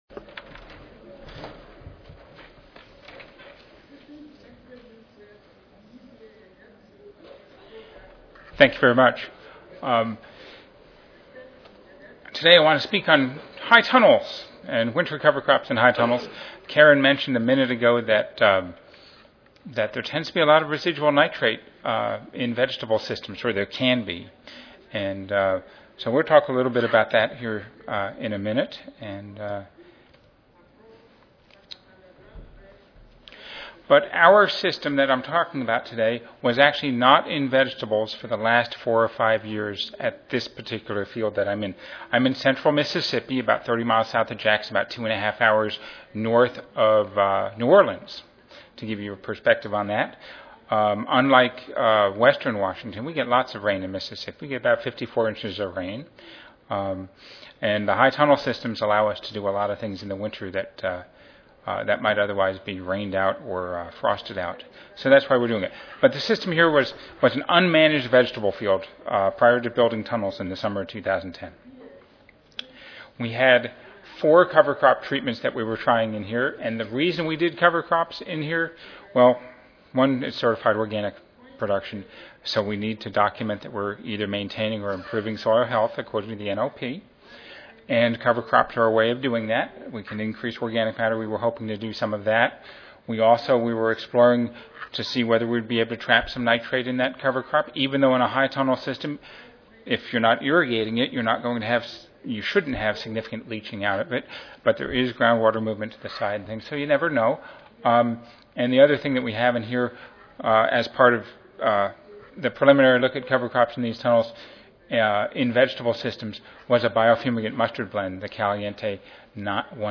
Mississippi State University Recorded Presentation Audio File